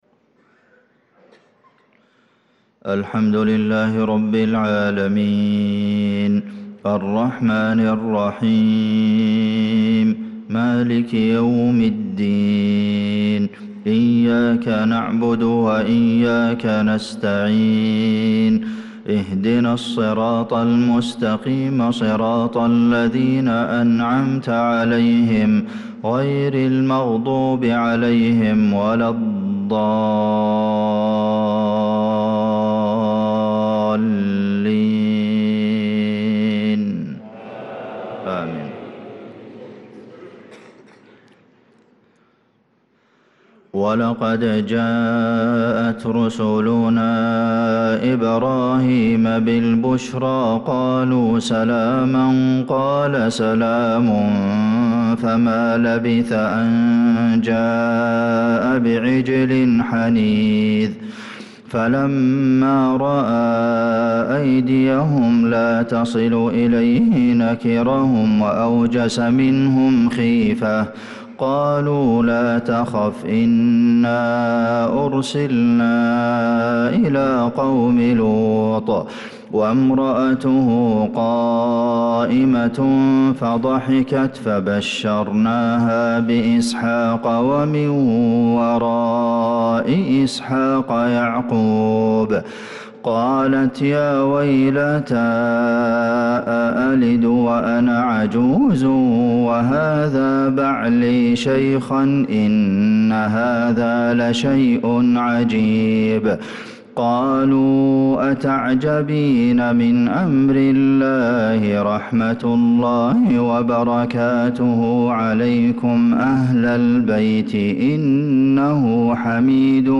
صلاة الفجر للقارئ عبدالمحسن القاسم 29 ذو الحجة 1445 هـ
تِلَاوَات الْحَرَمَيْن .